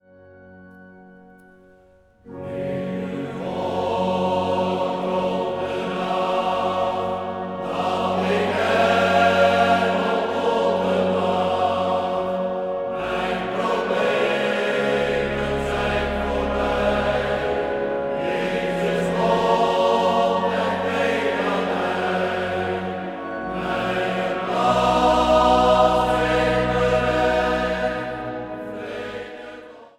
orgel.
Zang | Mannenkoor